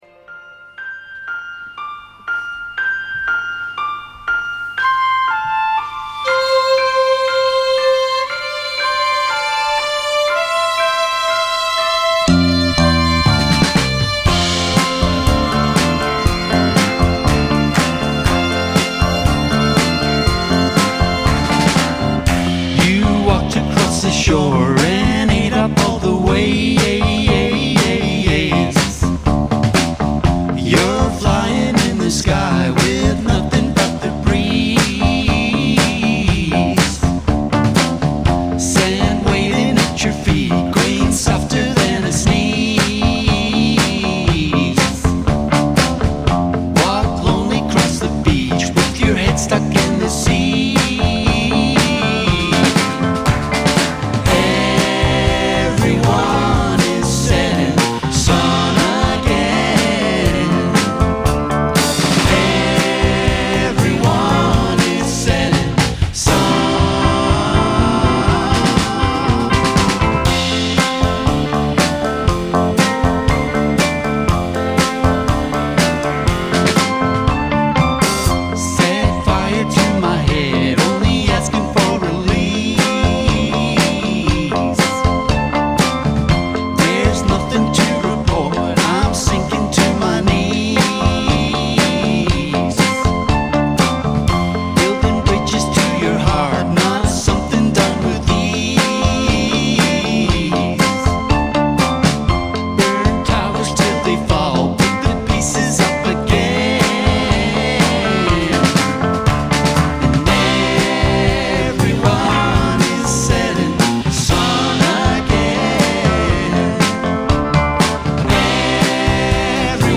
in session
their music is heavily influenced by 1960s pop.